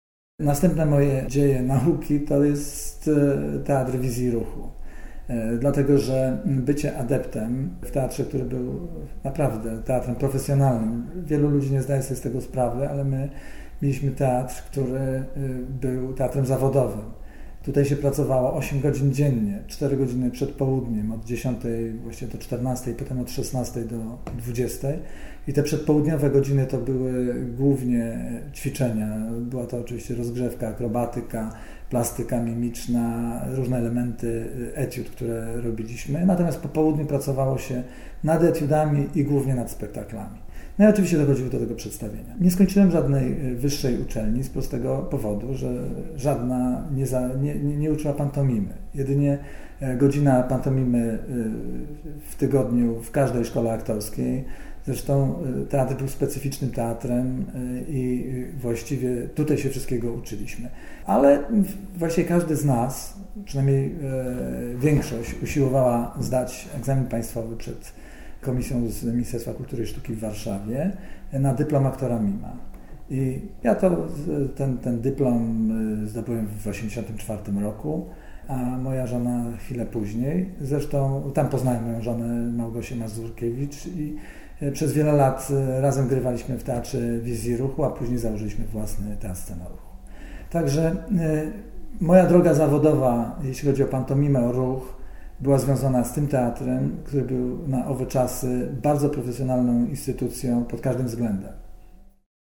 Relacja mówiona zarejestrowana w ramach Programu Historia Mówiona realizowanego w Ośrodku